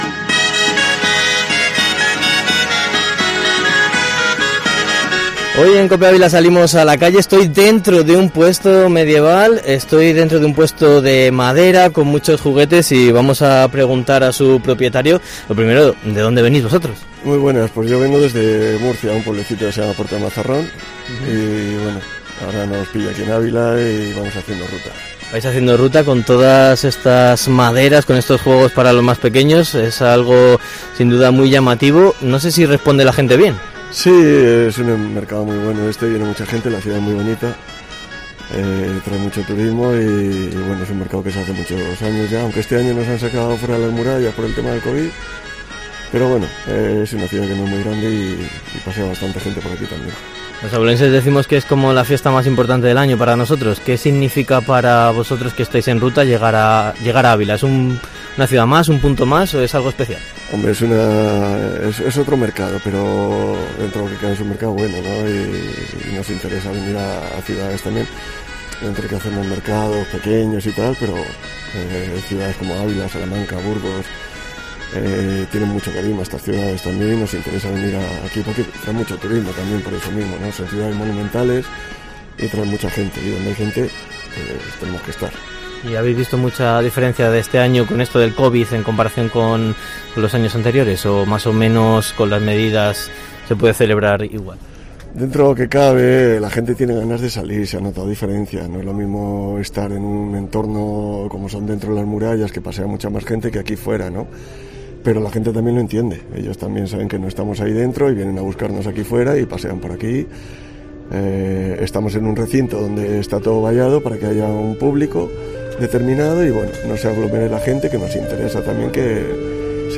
AUDIO / El Mercado Medieval de Ávila en sonidos
En COPE Ávila recorremos las calles y plazas del XXIV Mercado Medieval para hablar con abulenses, turistas y artesanos.